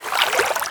sound / steps / water1.wav
water1.wav